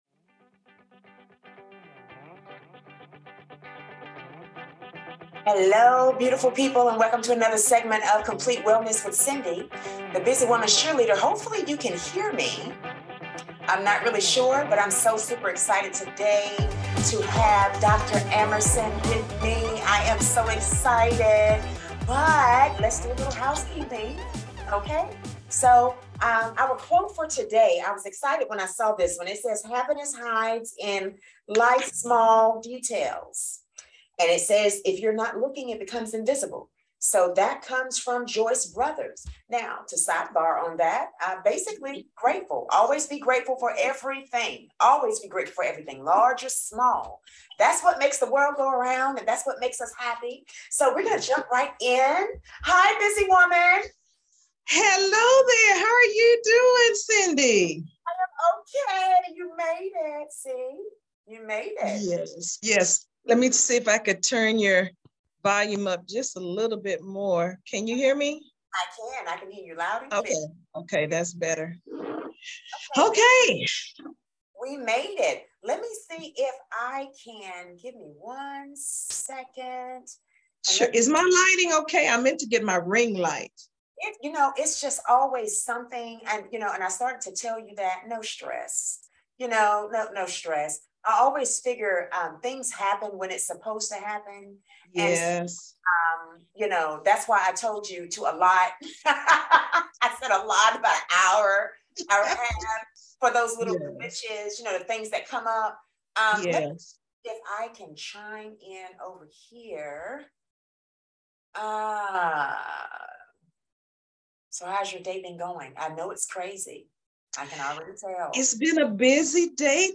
Why Do Busy Women Lose Their Hair? Interview